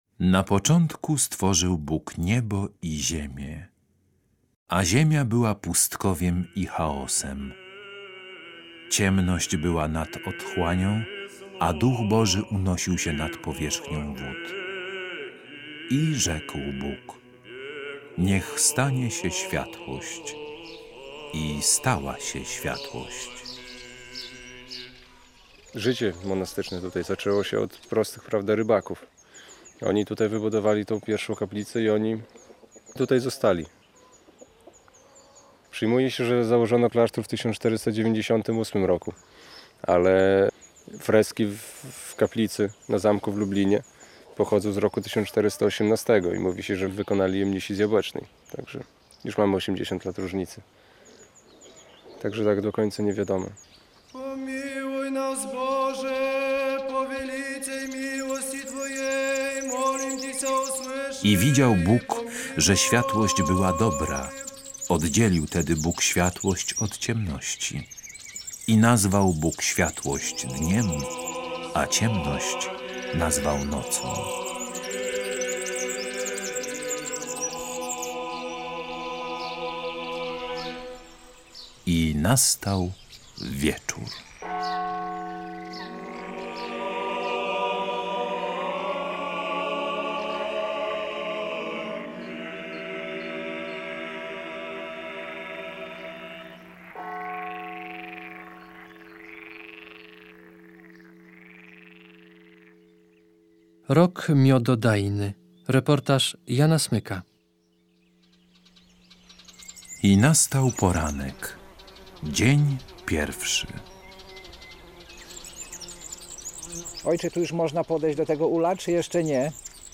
Miejsce nagrania: monaster św. Onufrego w Jabłecznie